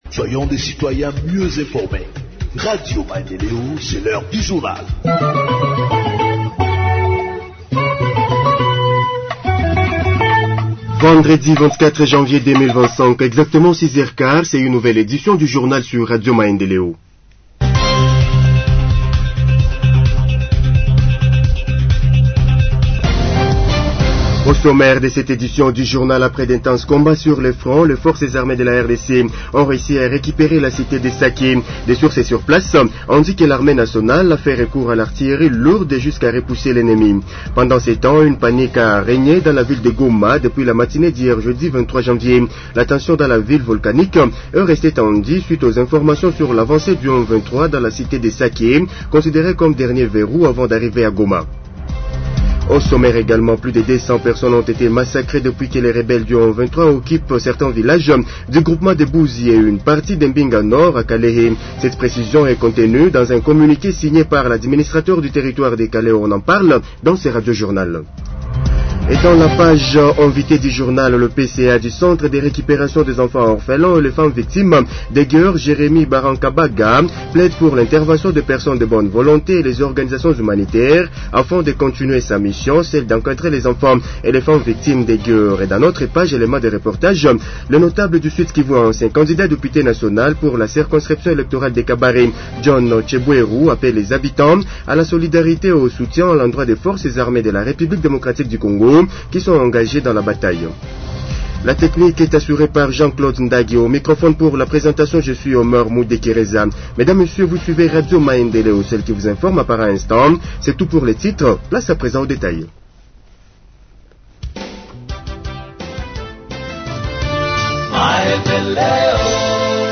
Journal français du 24 janvier 2025 – Radio Maendeleo